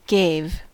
Ääntäminen
IPA : /ɡeɪv/